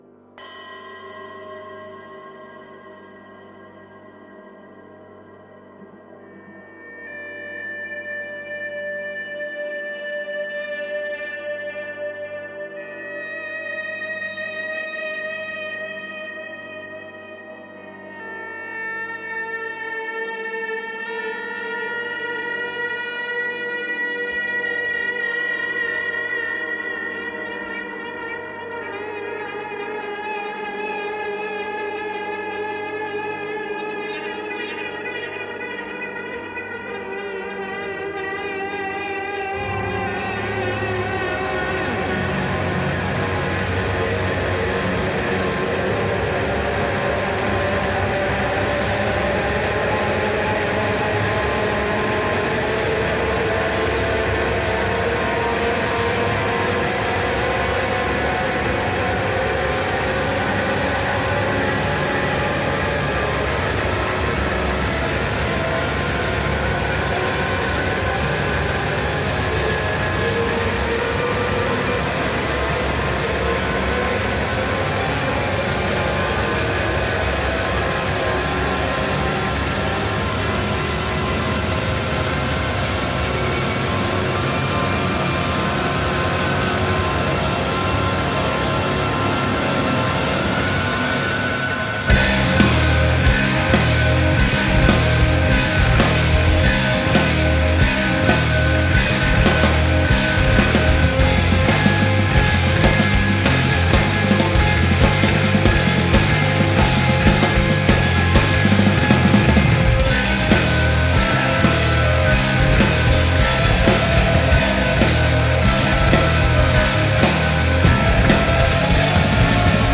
Black Metal, Avantgarde Metal